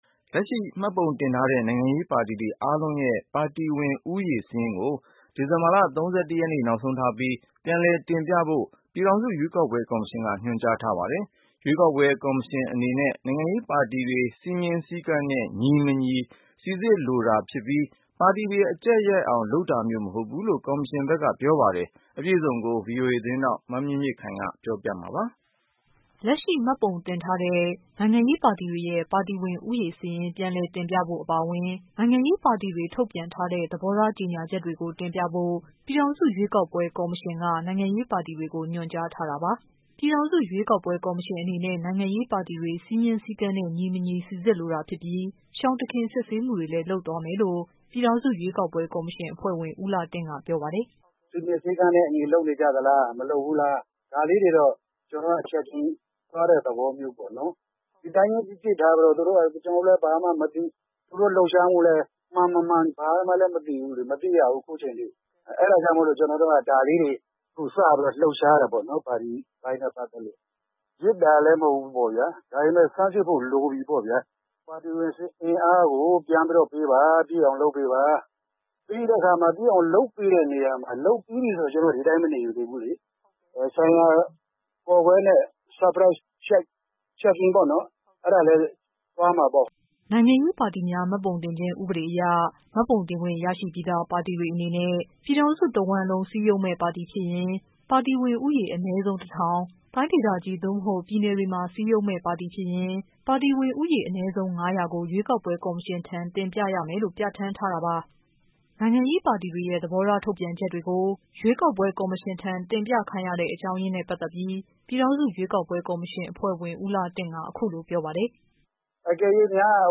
နိုင်ငံရေးပါတီတွေရဲ့ သဘောထားထုတ်ပြန်ချက်တွေကို ရွေးကောက်ပွဲ ကော်မရှင်ထံ တင်ပြခိုင်းရတဲ့ အကြောင်းရင်းနဲ့ ပတ်သက်ပြီး ပြည်ထောင်စု ရွေးကောက်ပွဲ ကော်မရှင် အဖွဲ့ဝင် ဦးလှတင့်က အခု လို ပြောပါတယ်။